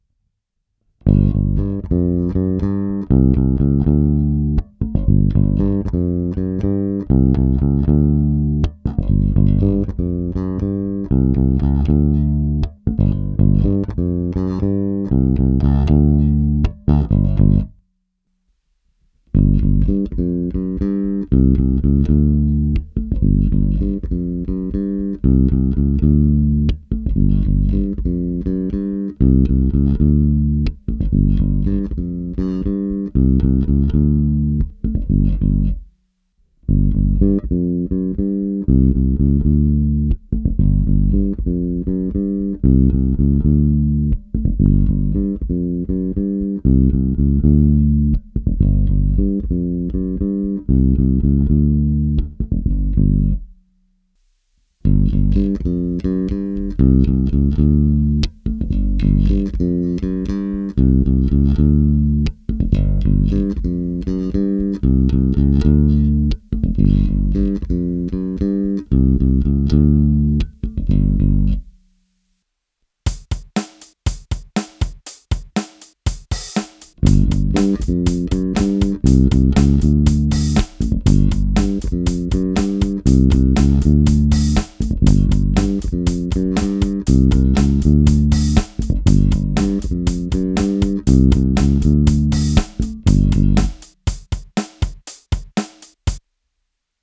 Velmi příjemné, měkčí zvukové podání.
Čeho jsem si ale všiml na první zapojení, je celkem výrazný šum.
V následující ukázce s baskytarou Ernie Ball Music Man StingRay 5 je v první části čistý zvuk baskytary přímo do zvukovky, pak přes VOX amPlug Bass s ovladačem TONE ve středu, pak s úplně staženým a pak úplně naplno. Nakonec ještě s ovladačem TONE ve středu jsem nahrál ukázku zvuku bicích. Kompresor byl nastavený u všech ukázek ve střední (oranžové) poloze.